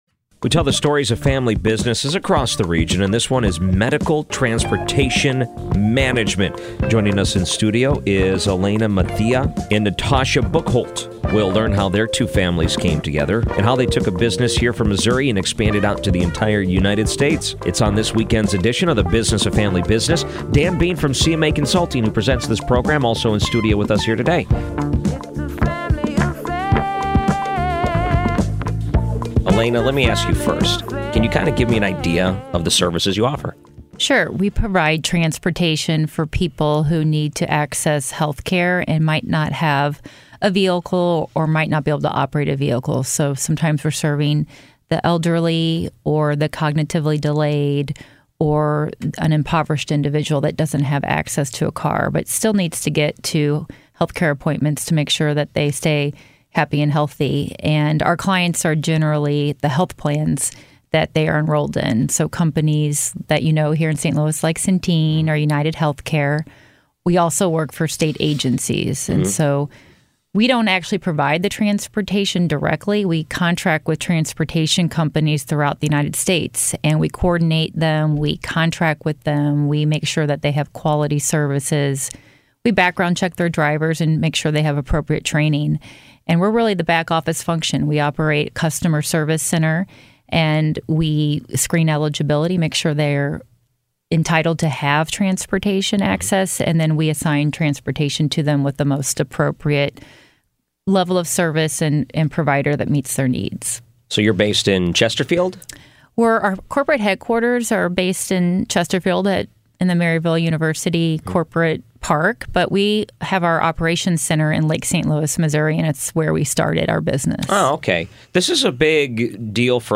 If you missed the live broadcast, you’re in luck – the recording is now available!